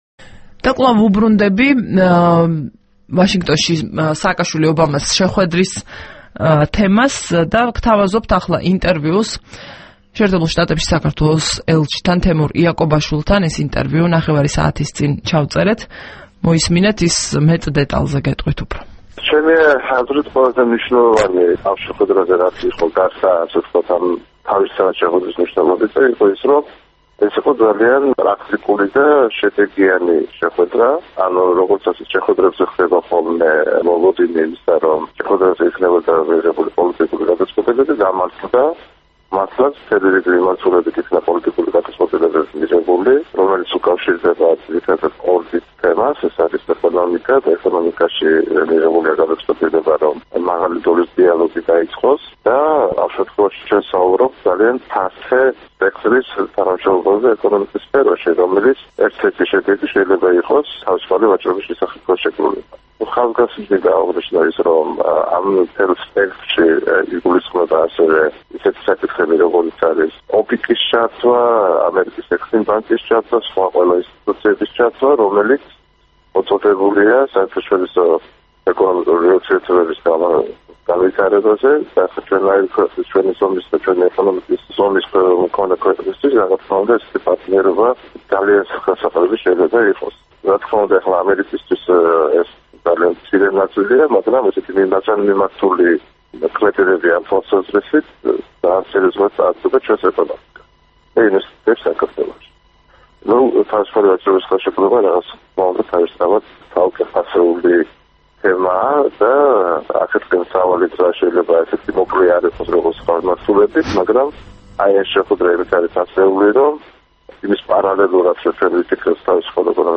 მიხეილ სააკაშვილისა და ბარაკ ობამას შეხვედრის შესახებ რადიო თავისუფლებას ესაუბრება საქართველოს ელჩი ამერიკის შეერთებულ შტატებში, თემურ იაკობაშვილი. მან "პრაქტიკული და შედეგიანი" უწოდა საქართველოსა და ამერიკის პრეზიდენტების შეხვედრას, რომელმაც პოლიტიკური გადაწყვეტილებების მიღების თაობაზე საზოგადოების მოლოდინი გაამართლა.
საუბარი თემურ იაკობაშვილთან